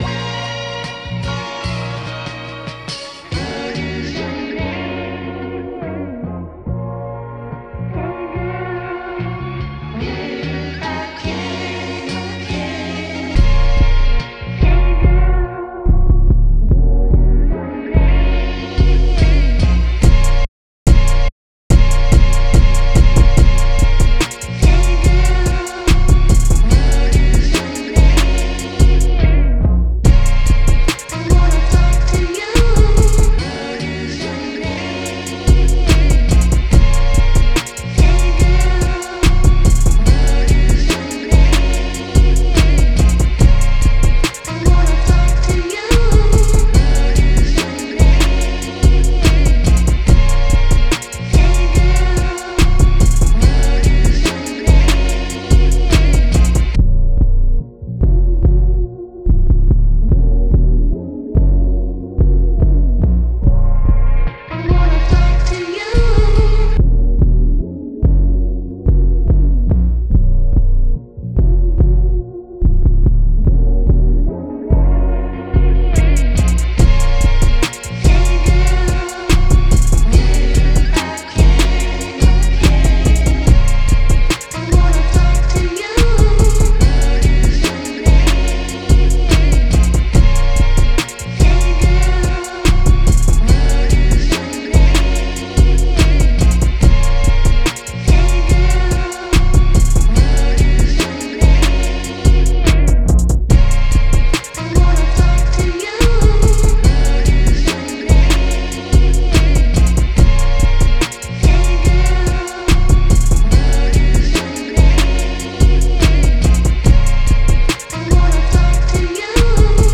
Sampling Beat
For this beat, I found another sample that I really liked in a sample pack and decided to pitch it up a little and chop it to my liking. After this, I made a drum rack out of drum samples from one of my sample packs and came up with a drum pattern that fit the song nicely. Next, I went through multiple bass sounds but finally found the one that I thought fit the best.